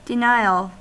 Ääntäminen
US : IPA : [dɪ.ˈnaɪ.əl] UK : IPA : [dɪˈnaɪ(j)əɫ]